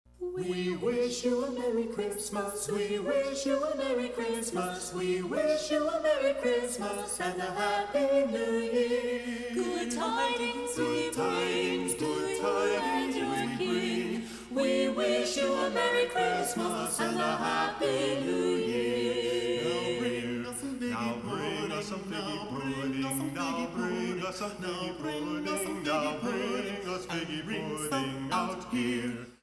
The 56-member ensemble is comprised of 14 vocal quartets in traditional Charles Dickens style costumes and beautiful 4-part harmony.
Traditional and modern arrangements of your favorite holiday music performed in beautiful 4-part harmony.